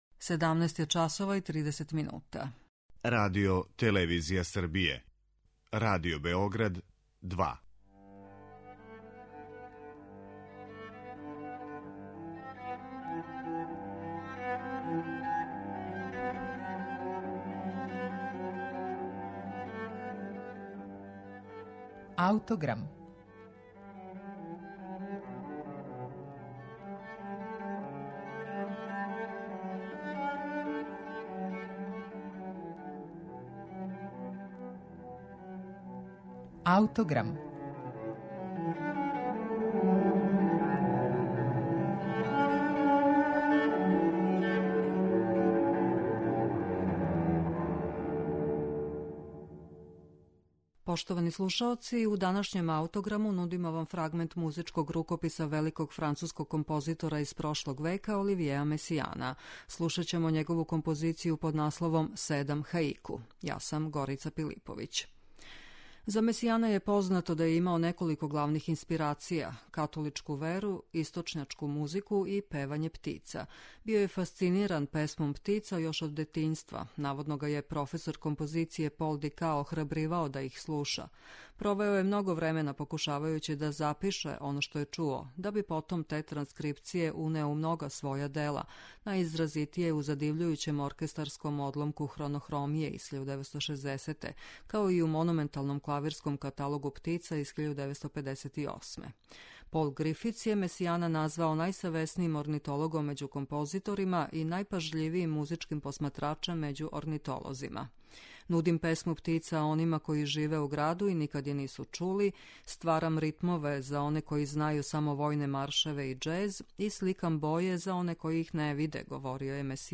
у којој је такође употребио песму птица.